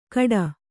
♪ kaḍa